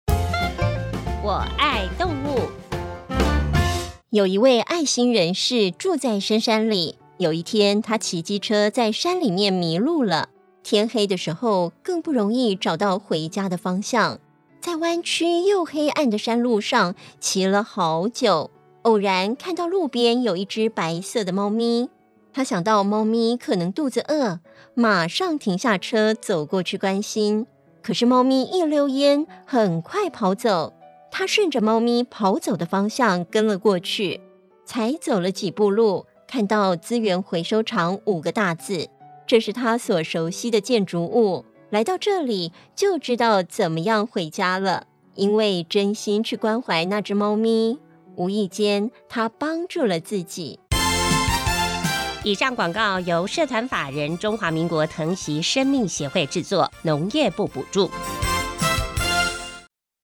「我愛動物」系列廣播廣告文稿～第 5 集 ～片頭 主講人： 有一位愛心人士，住在深山裏，有一天，他騎機車在山裏面迷路了，天黑的時候，更不容易找到回家的方向，在彎曲又黑暗的山路上，騎了好久，偶然看到路邊有一隻白色的貓咪，他想到貓咪可能肚子餓，馬上停下車，走過去關心。